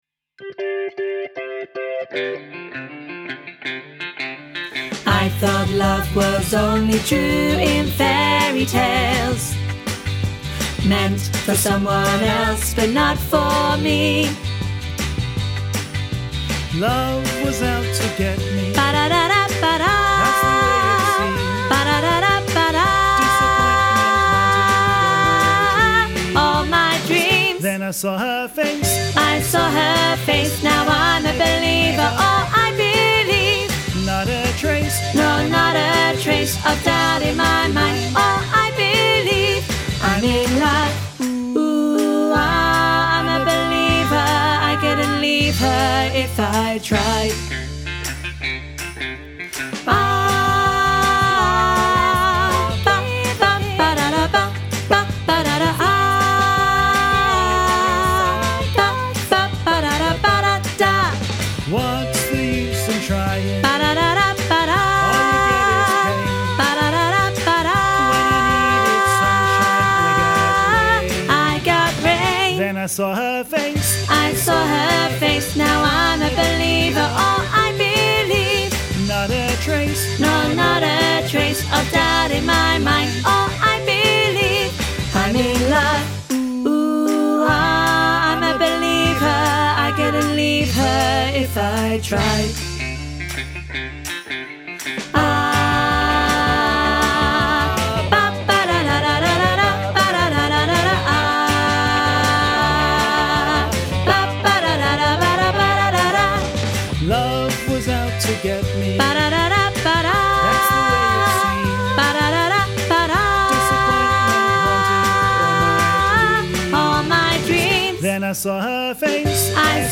Training Tracks for I'm a Believer
im-a-believer-alto-half-mix.mp3